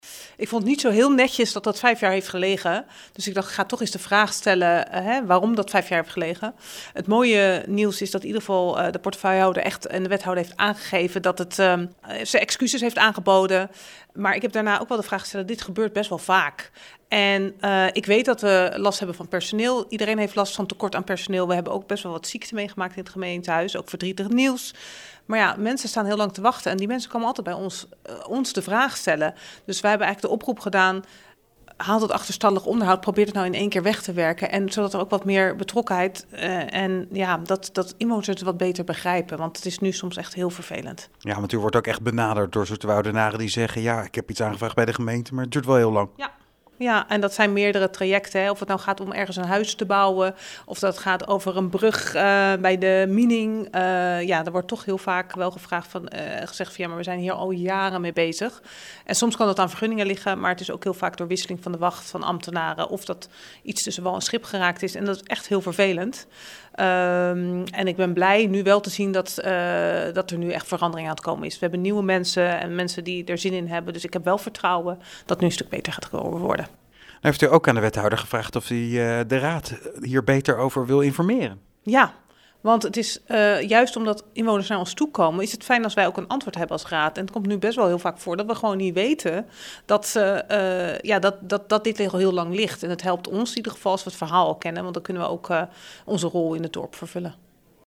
VVD Zoeterwoude fractievoorzitter Kitty de Lange-De Heiden over de aanvraag die vijf jaar bleef liggen.